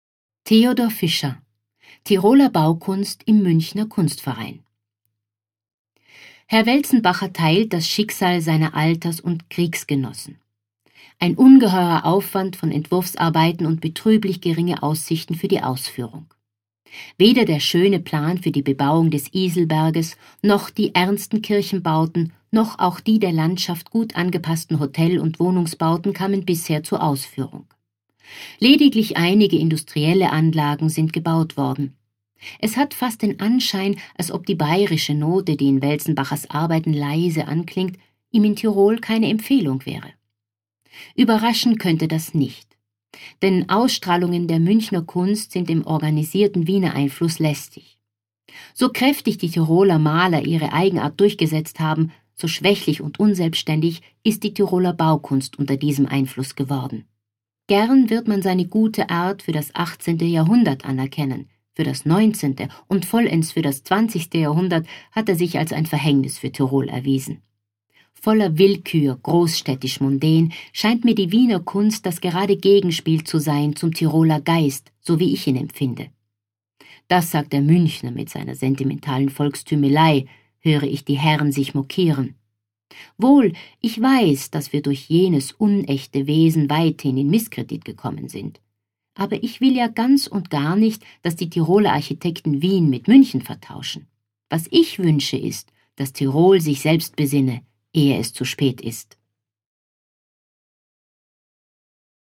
Das anlässlich der Eröffnung von aut im Adambräu erscheinende Lesebuch „reprint“ war die Basis für 11 Hörstationen in der 2005 gezeigten Eröffnungsausstellung vermessungen.